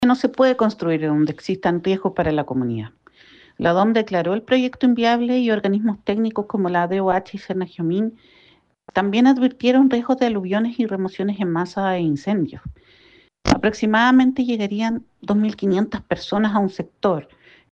La concejala Nancy Díaz respaldó las demandas de la comunidad, y enfatizó la necesidad de priorizar el bienestar de los vecinos y la protección del territorio por sobre intereses inmobiliarios.